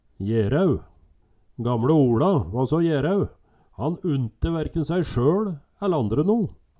Høyr på uttala Ordklasse: Adjektiv Kategori: Karakteristikk Attende til søk